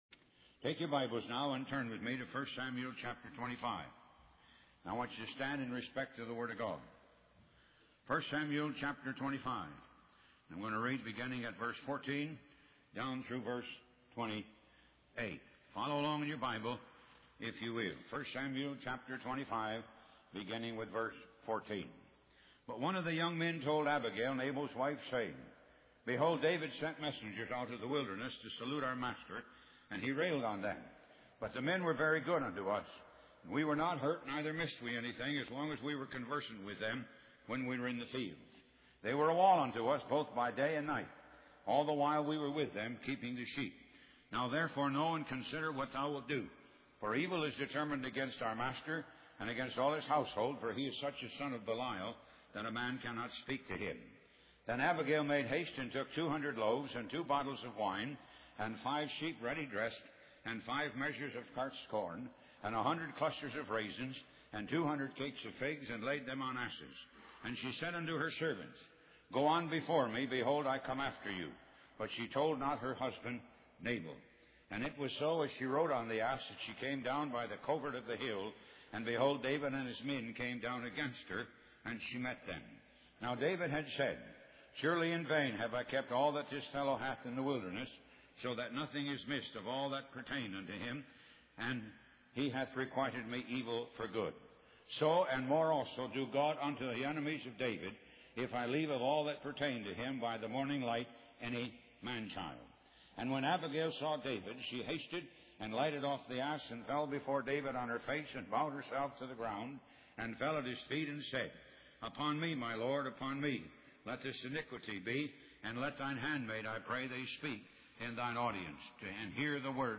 Talk Show Episode, Audio Podcast, Moga - Mercies of God Association and The Decisions of an Awakened Sinner on , show guests , about The Decisions of an Awakened Sinner, categorized as Health & Lifestyle,History,Love & Relationships,Philosophy,Psychology,Christianity,Inspirational,Motivational,Society and Culture